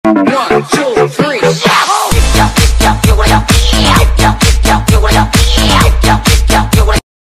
SFX一给我里giao旋律音效下载
SFX音效